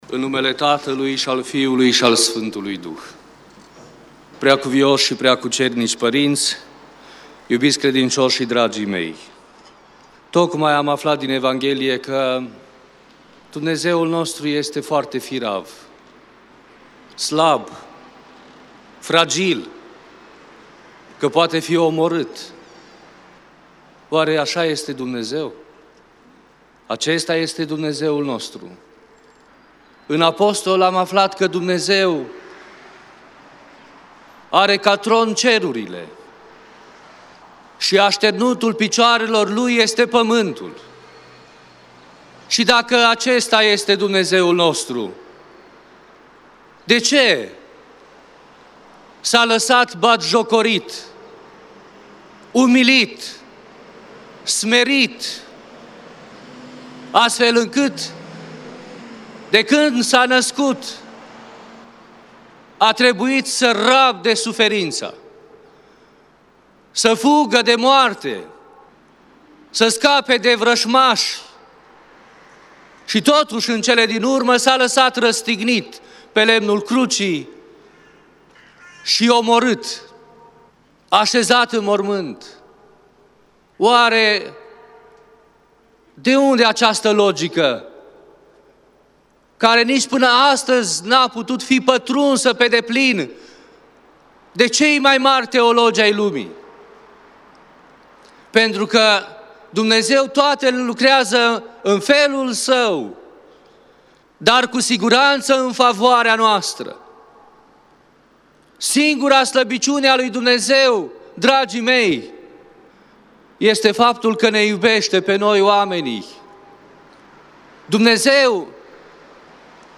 Cuvinte de învățătură